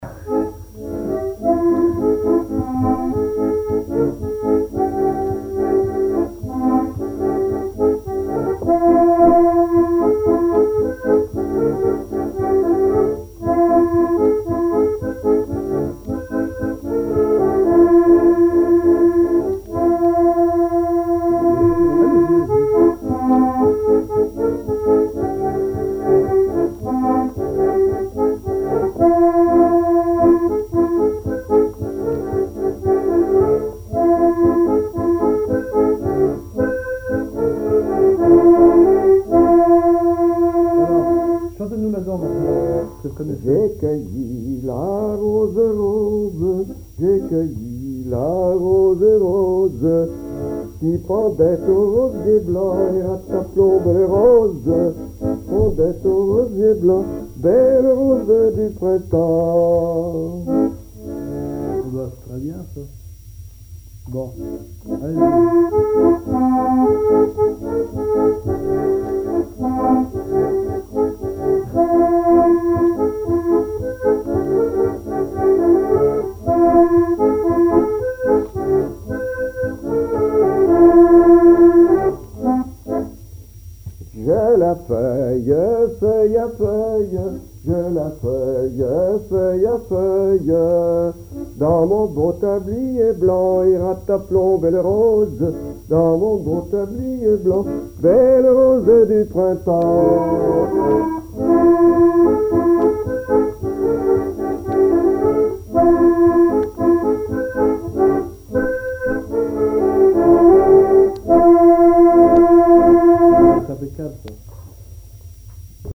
Enquête Arexcpo en Vendée
Pièce musicale inédite